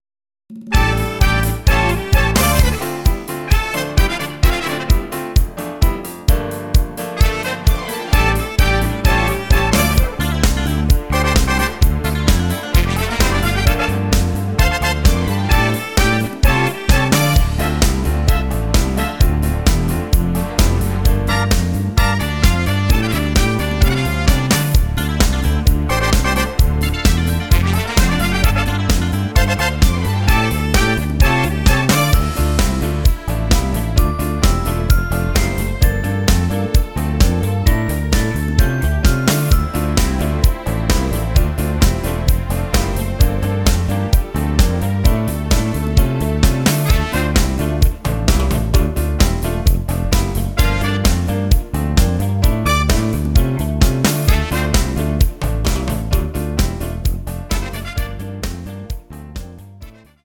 Super Groove, der geht ab!
Rhythmus  70-er Disco